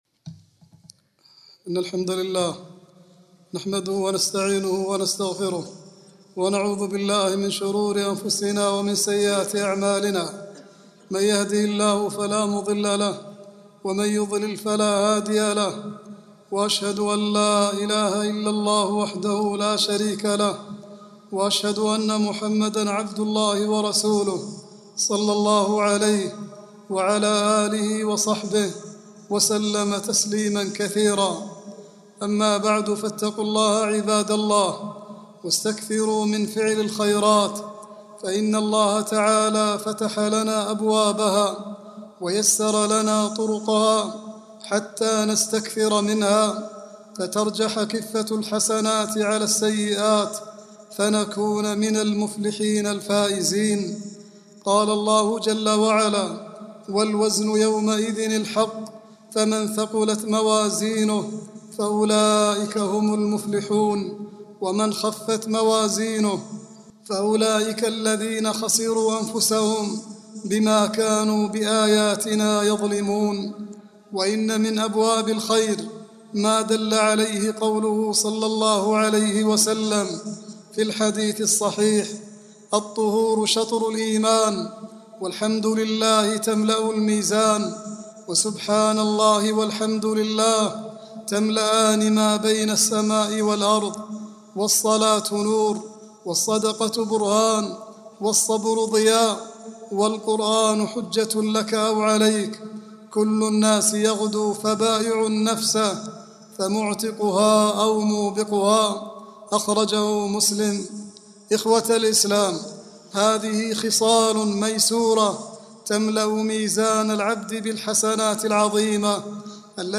khutbah-26-3-39.mp3